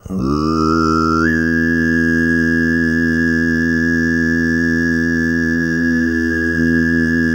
TUV3 DRONE08.wav